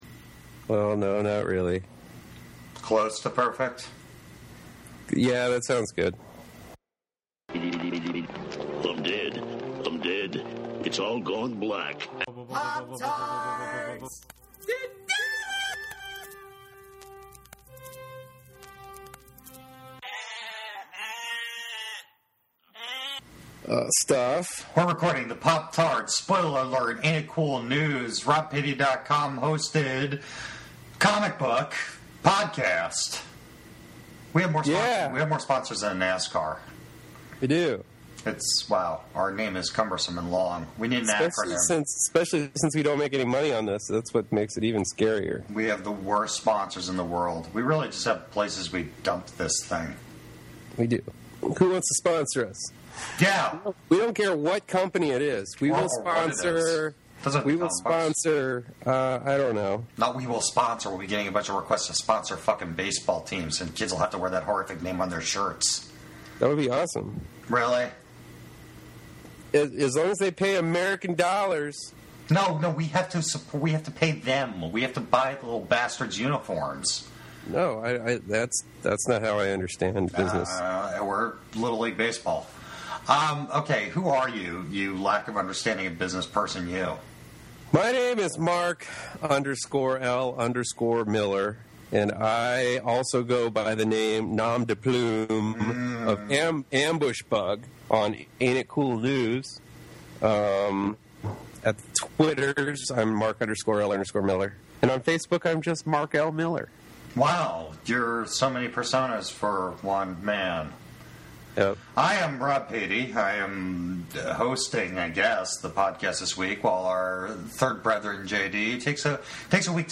STRONG LANGUAGE AND THEMES AHEAD. NOT ADULT THEMES.